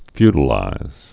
(fydl-īz)